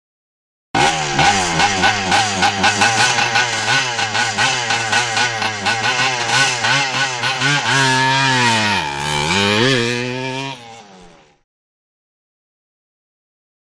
Motorcycle; Away / Crash; Scrambles Motorbike Rev, Away And Crashes.